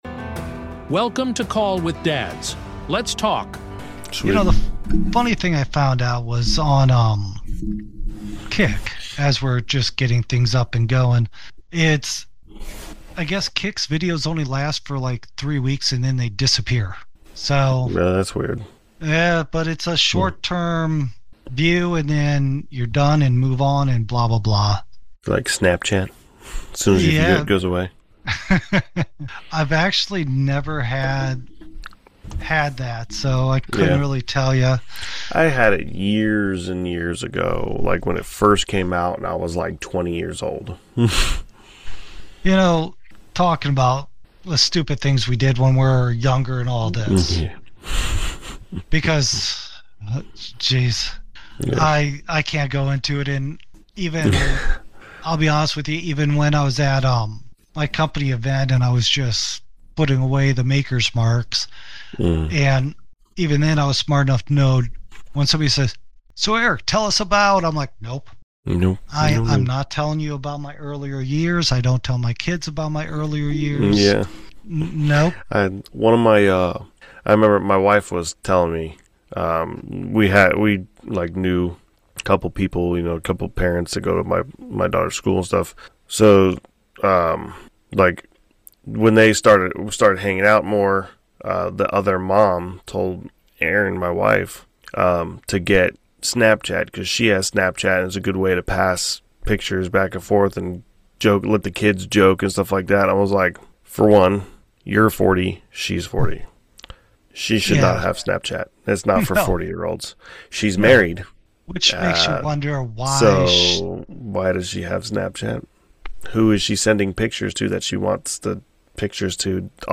No guest this episode—it’s a raw, hosts-only conversation